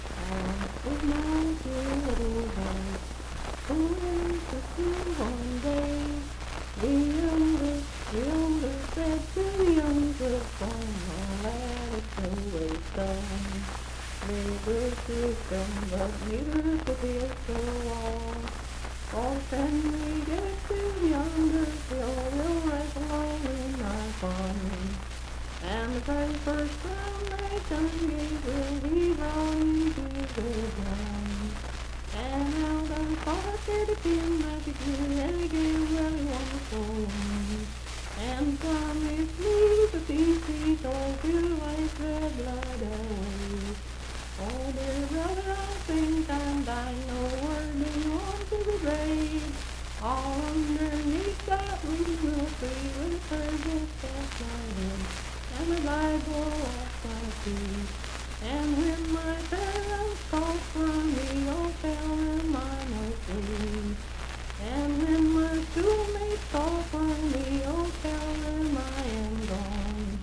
Unaccompanied vocal music
Voice (sung)
Hardy County (W. Va.), Moorefield (W. Va.)